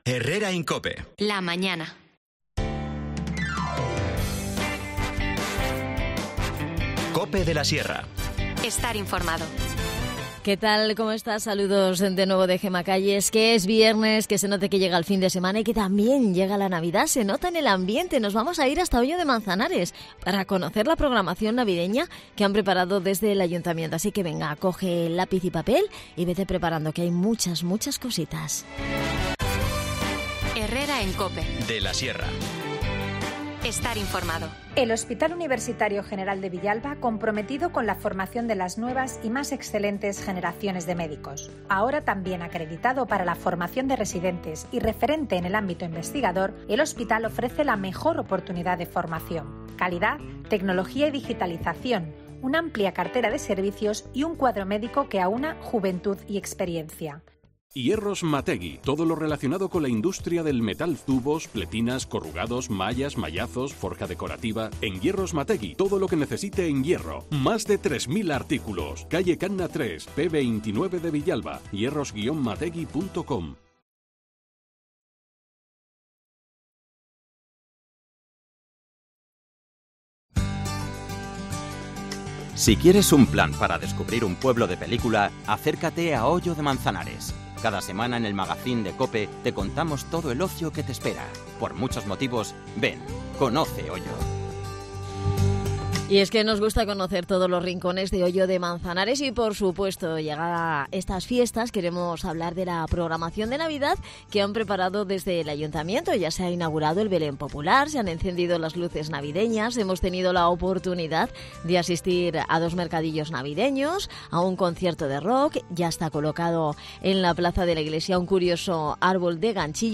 Charlamos con Juan José Crespo, concejal Festejos en Hoyo de Manzanares.